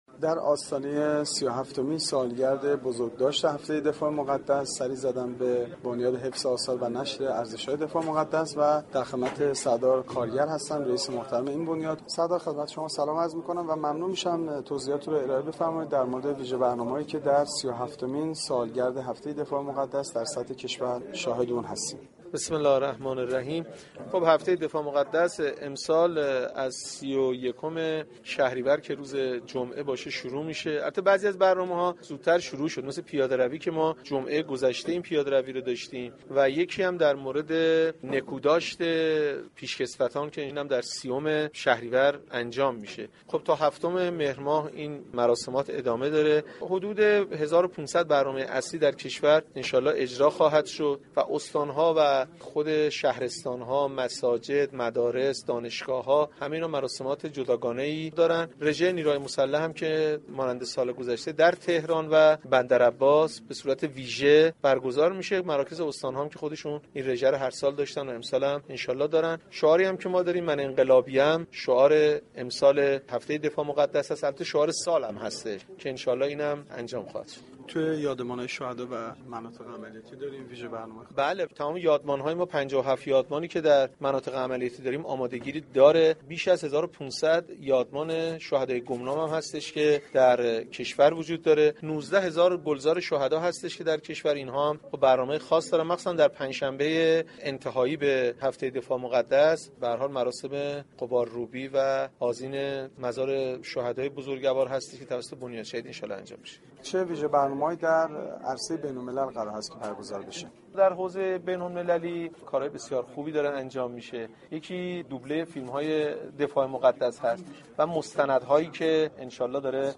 در آستانه ی سی و هفتمین سالگرد بزرگداشت هفته ی دفاع مقدس ، سردار بهمن كارگر رئیس ستاد مركزی بزرگداشت هفته دفاع مقدس و رییس بنیاد حفظ آثار و نشر ارزش های دفاع مقدس در گفتگوی اختصاصی با گزارشگر رادیو فرهنگ ، در تشریح برنامه های این هفته گفت : برخی از برنامه ها همچون پیاده روی و نكوداشت پیشكسوتان قبل از هفته ی دفاع مقدس آغاز شده است .